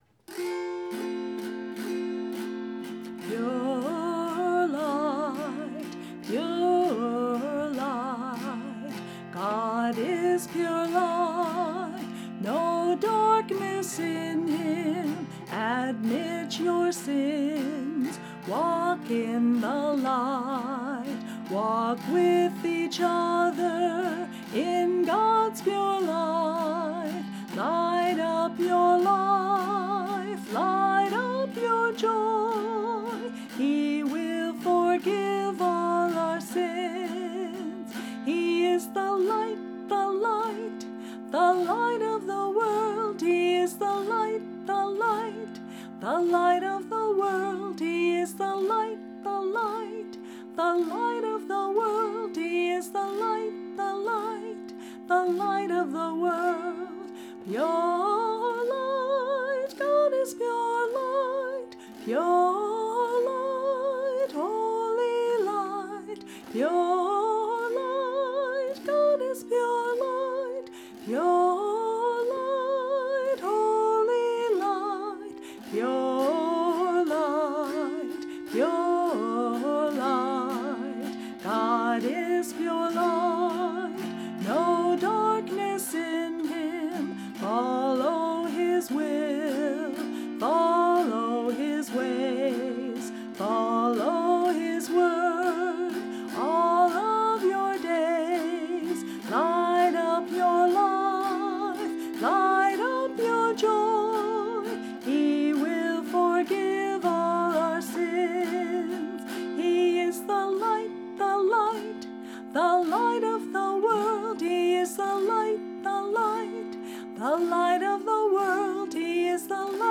This entry was posted in Christian Music, Uncategorized and tagged , , , , , , , , , .